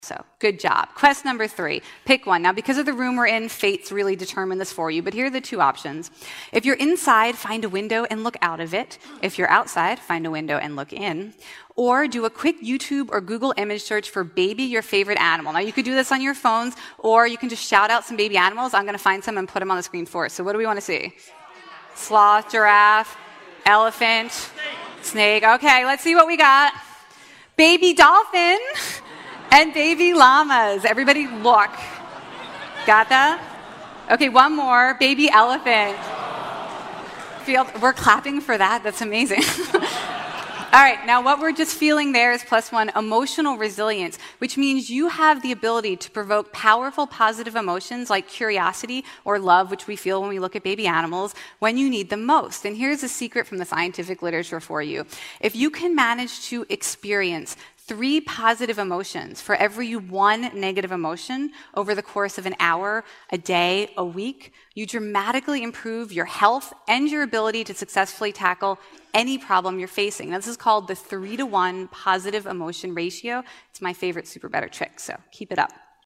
TED演讲：能让你多出10年额外寿命的游戏(9) 听力文件下载—在线英语听力室